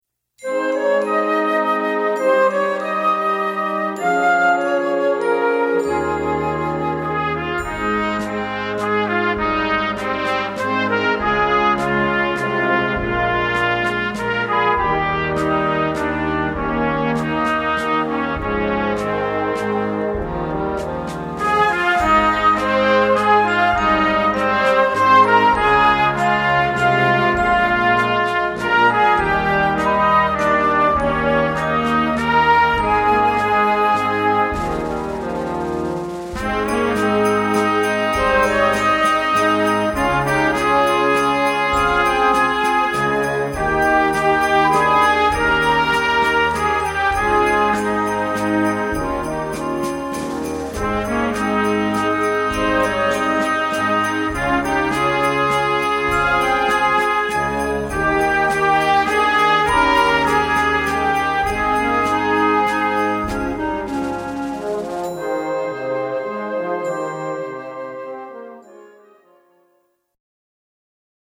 Gattung: Blasmusik für Jugendkapelle
Besetzung: Blasorchester